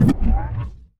Robotic Back Button 4.wav